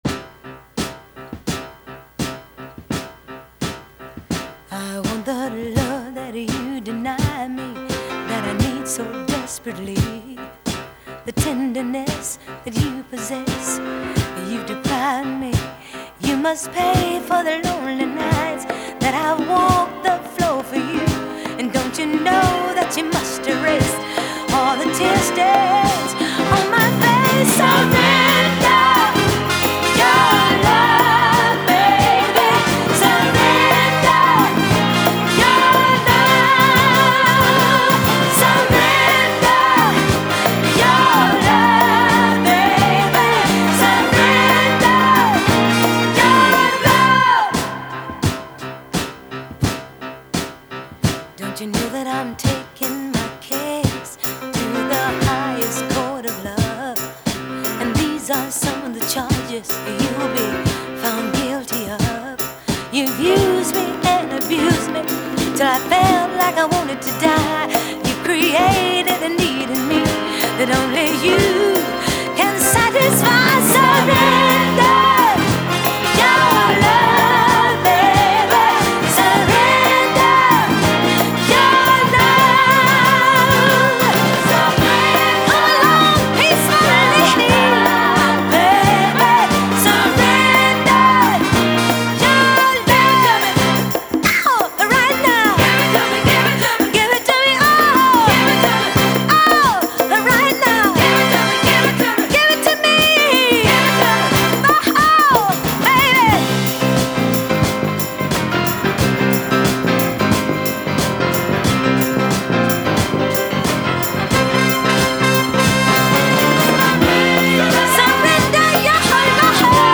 Soul / R&B